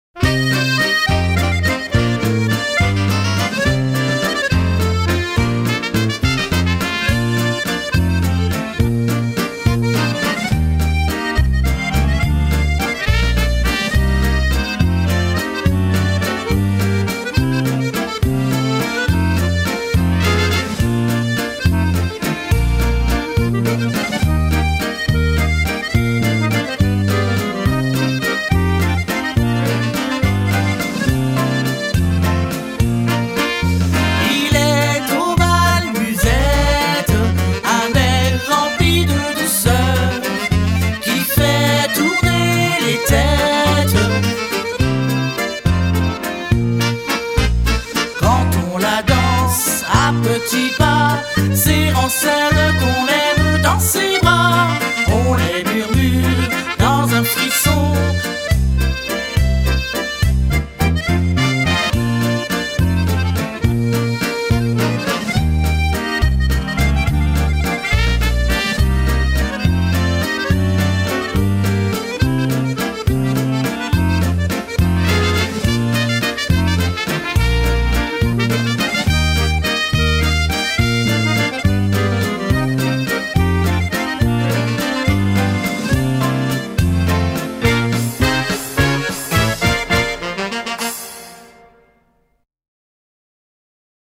La version chantée avec les trous (Expert)